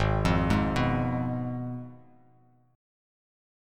G#m6 Chord